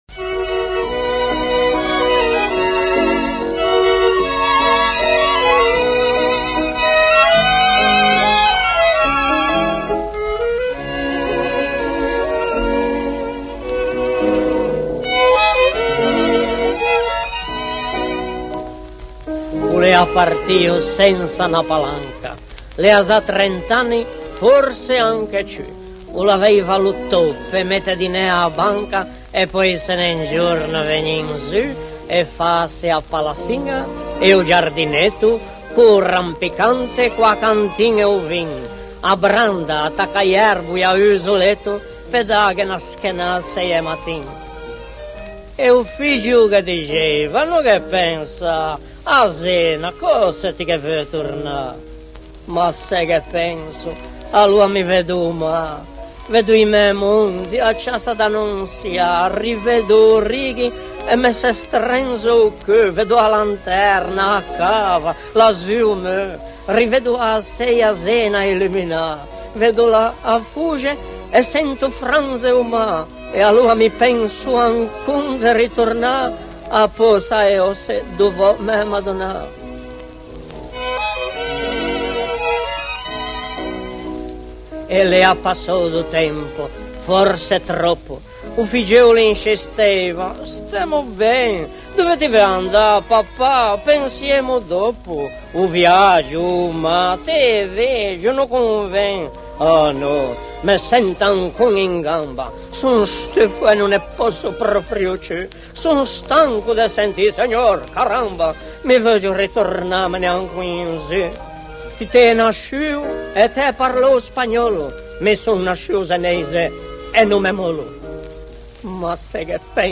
Altre musiche e canti popolari tradizionali: